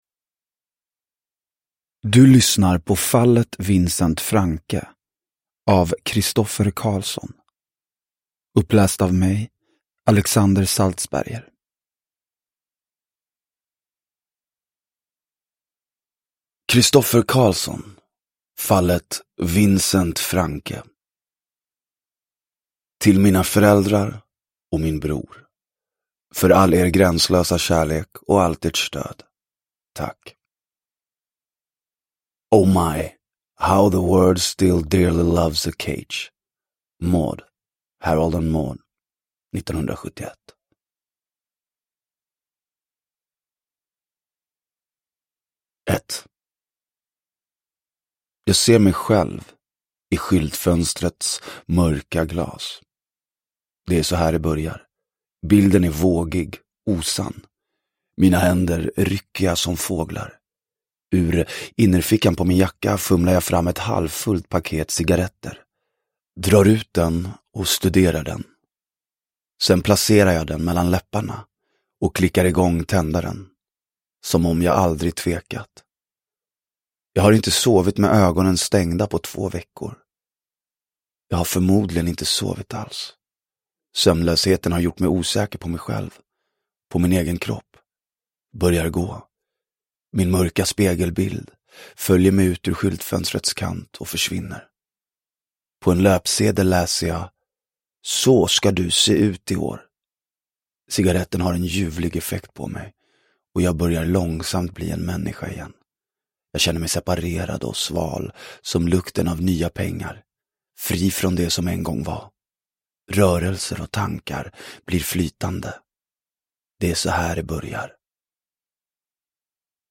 Fallet Vincent Franke (ljudbok) av Christoffer Carlsson